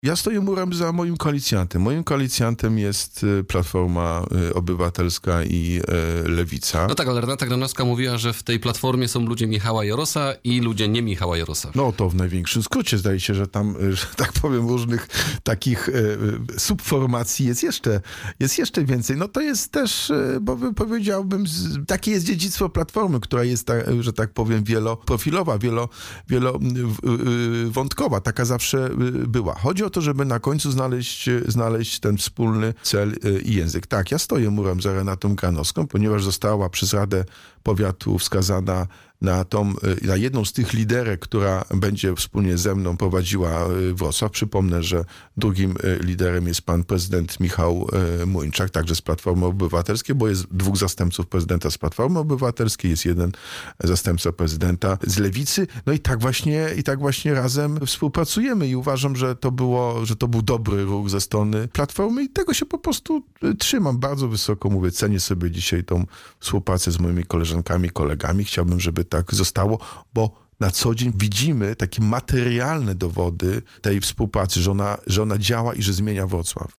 – Staram się szukać przebaczenia i wybaczać nawet tym, którzy czynią mi źle – powiedział w rozmowie z Radiem Rodzina prezydent Wrocławia, Jacek Sutryk.